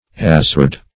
azured - definition of azured - synonyms, pronunciation, spelling from Free Dictionary
Azured \Az"ured\, a.